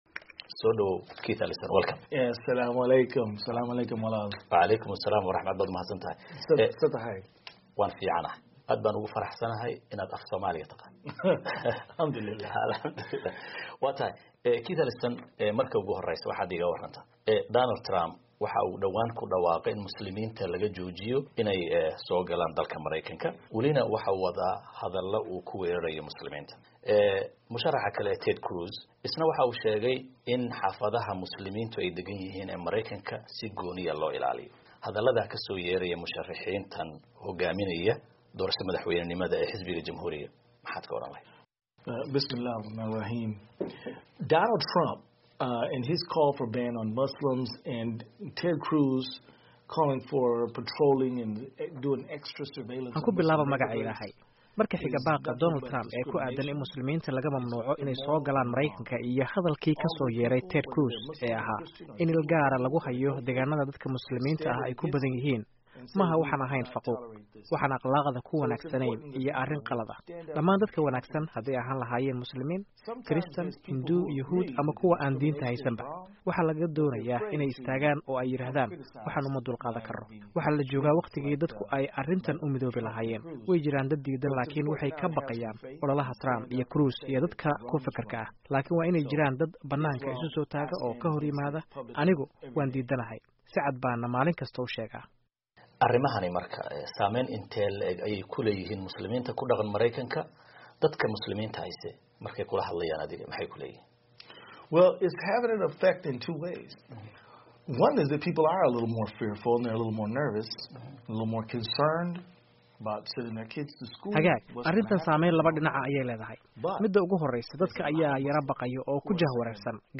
Dhegayso: Waraysiga Mudane Keith Ellison oo dhamaystiran